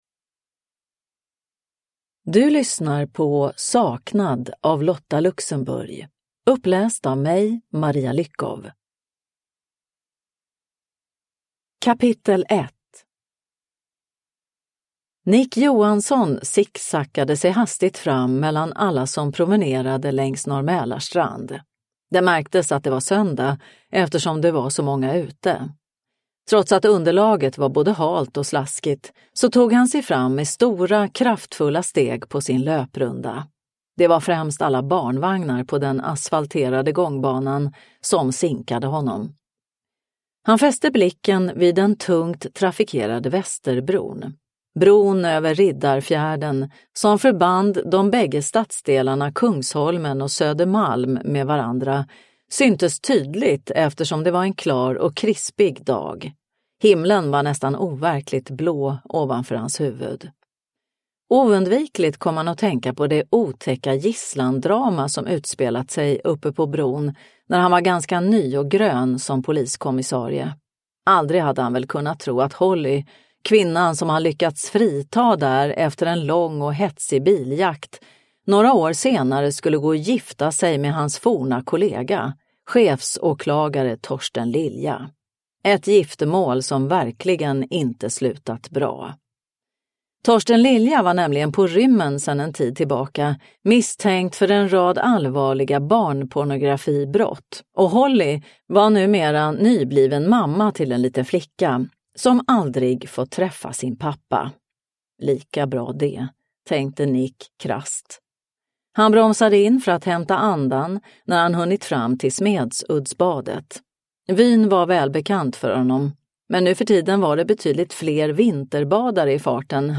Saknad – Ljudbok – Laddas ner